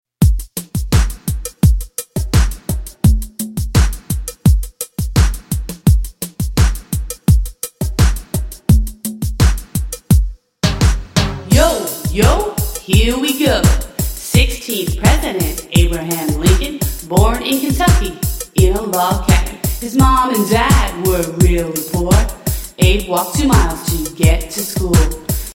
MP3 Demo & Rap
MP3 Demo Vocal Track